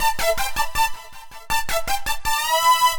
Index of /musicradar/future-rave-samples/160bpm
FR_Arpune_160-A.wav